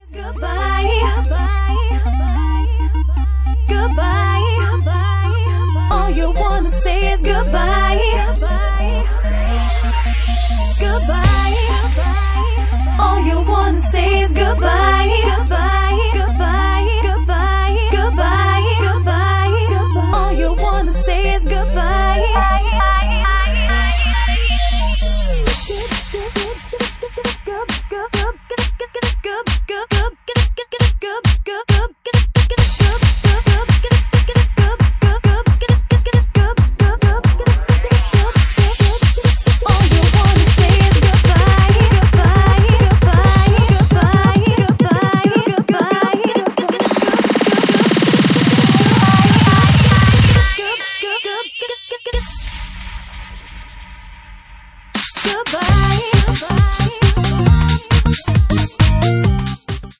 Genre:Bassline House
Bassline House at 136 bpm